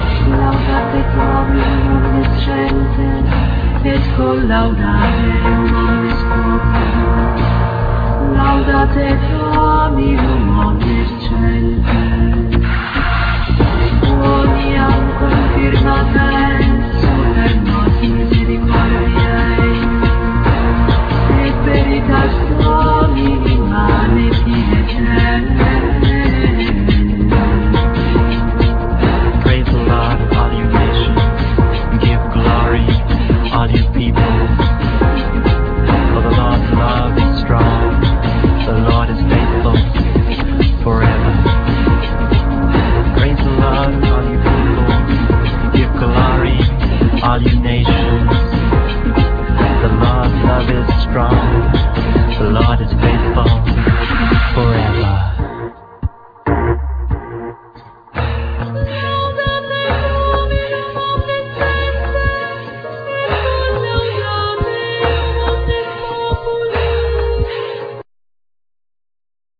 Piano, Keyboards
Voice
Cello
Rhythm programming, Sample, Loops
Live erectronics